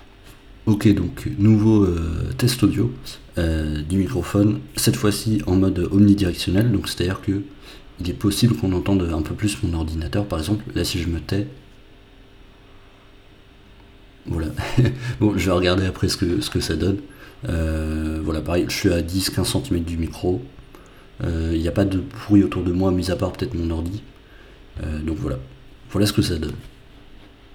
Mis à part ça, la qualité audio est très bonne, bien que pour ce prix-là (une centaine d’euros) on puisse trouver mieux.
Un enregistrement avec le microphone en mode omnidirectionnel
Test-audio-JBL-Qantum-Stream-mode-omnidirectionnel.wav